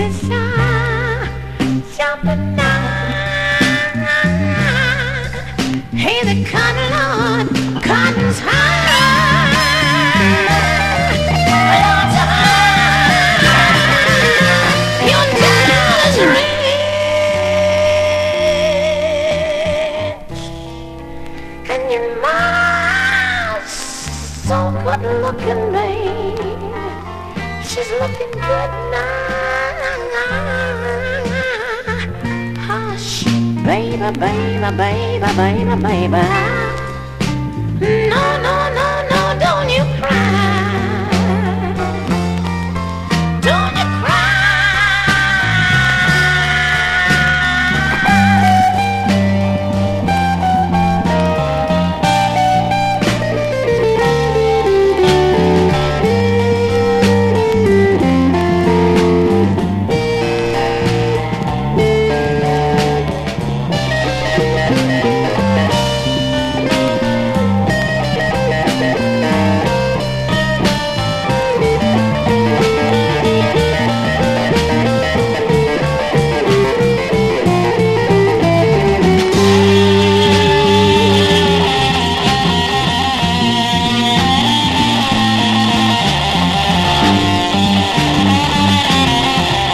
JAPANESE / 80'S / NEW WAVE / JAPANESE NEW WAVE (JPN)
ラララ・コーラスがキュートに響く